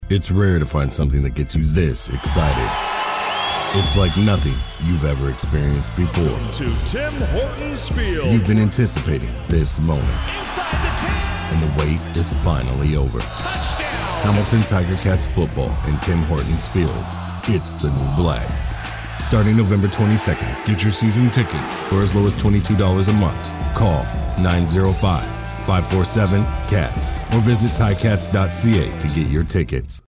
Deep rich voice with calm and energetic tones.
Sprechprobe: Sonstiges (Muttersprache):